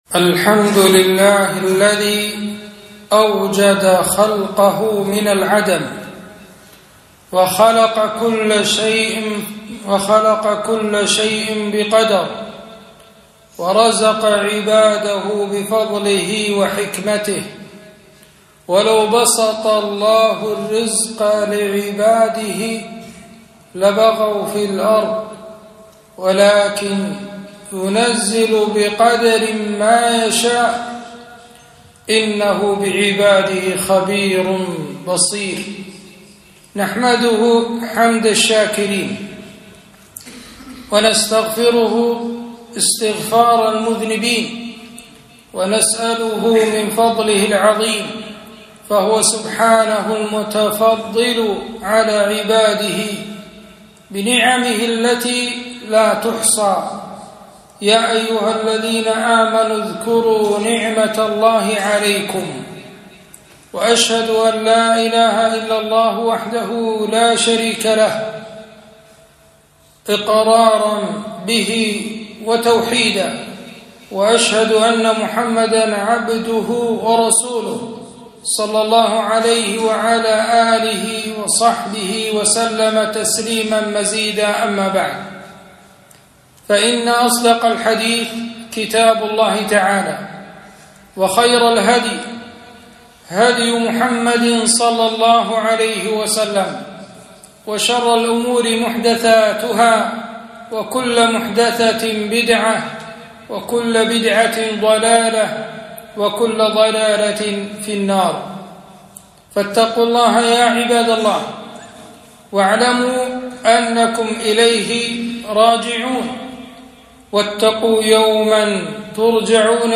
خطبة - نعمة المال